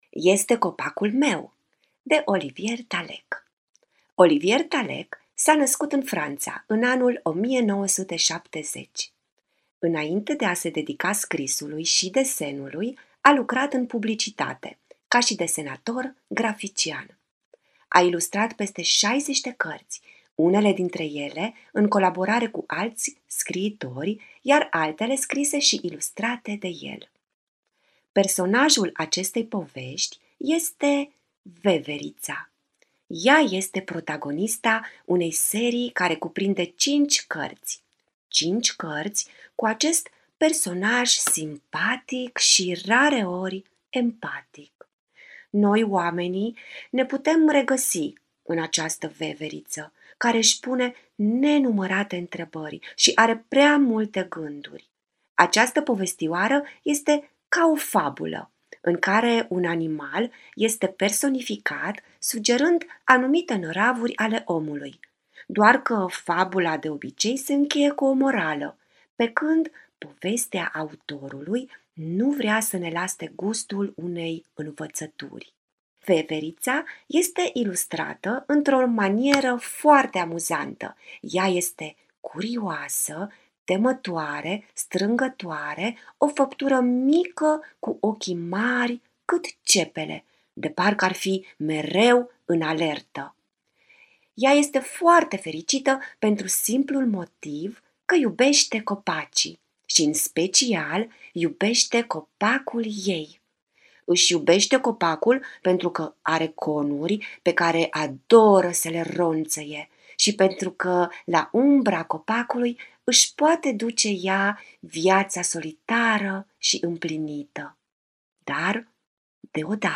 artist păpușar